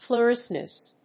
音節fluo・res・cence 発音記号・読み方
/flɔːr)résns(米国英語), ˌflʊˈresʌns(英国英語)/